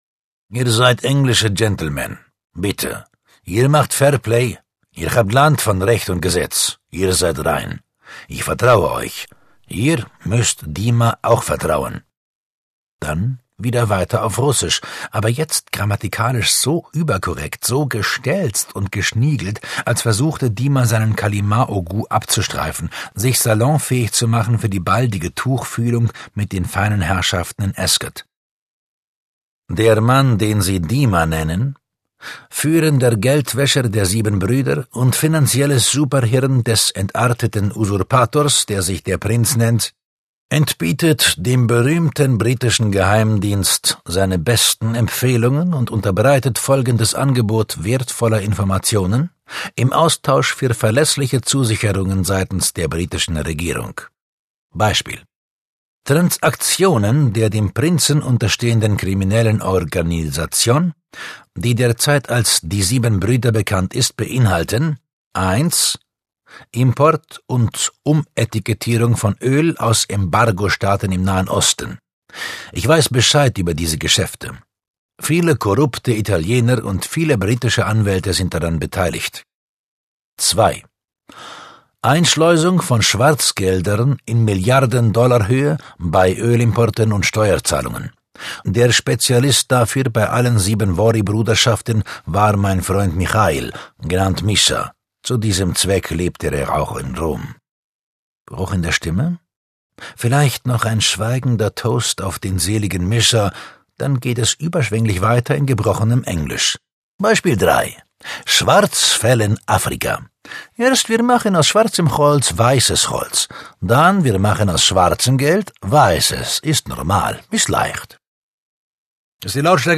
Details zum Hörbuch
In den letzten Jahren widmete sich Johannes Steck vorwiegend seiner Sprechertätigkeit im Hörbuch; die Virtuosität seiner Stimme macht ihn bis heute zu einem der bekanntesten und vielfältigsten deutschen Sprecher.